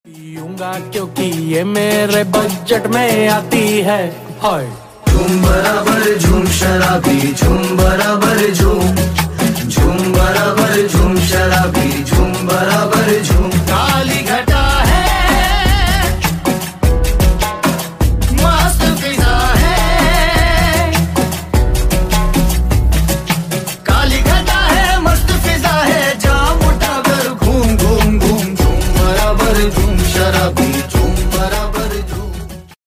energetic
powerful beats